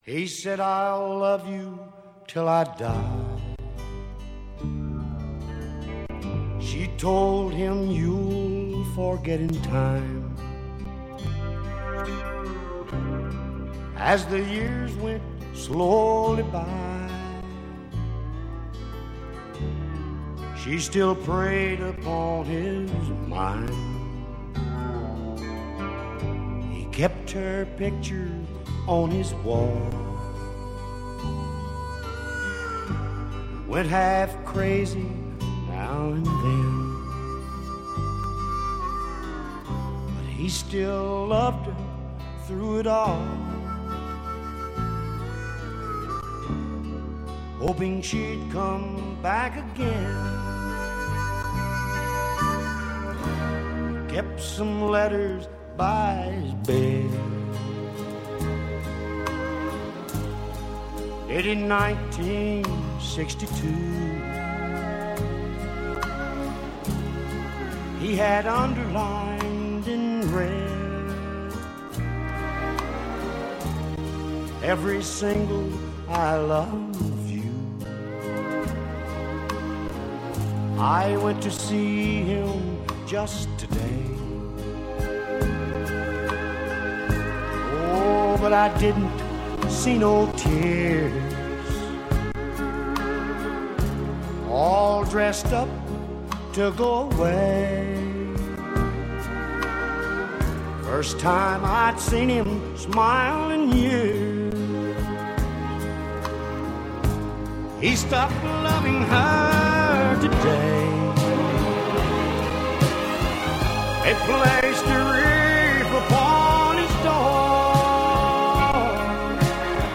Genre: Country.